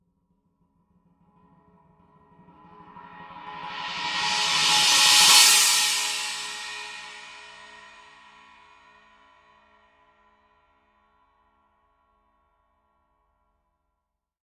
The Paiste 18 inch 2002 Nova China Cymbal is very bright, slightly trashy, full, exotic. Wide range, complex mix. Fast, responsive, explosive crash, loud, clear defined bell.
18_novo_china_roll.mp3